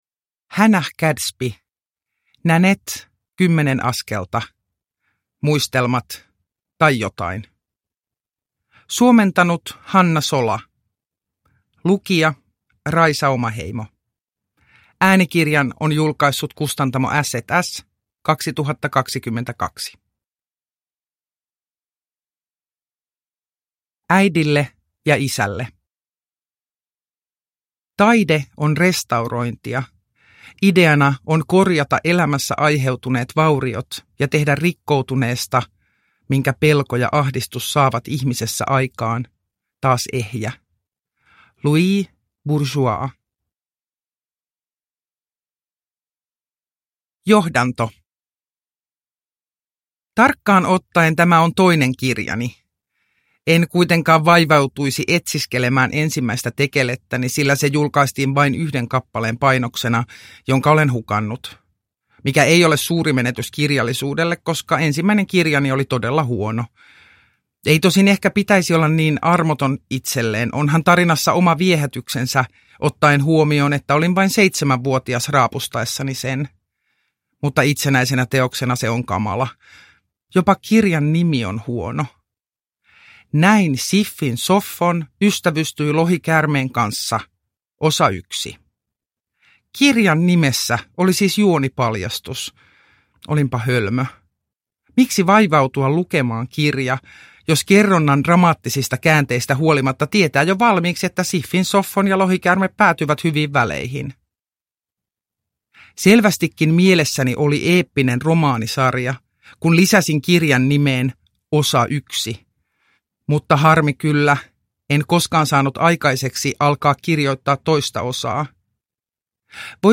Nanette - kymmenen askelta – Ljudbok – Laddas ner